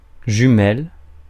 Ääntäminen
Synonyymit paire de jumelles longue-vue Ääntäminen Tuntematon aksentti: IPA: /ʒy.mɛl/ Haettu sana löytyi näillä lähdekielillä: ranska Käännöksiä ei löytynyt valitulle kohdekielelle.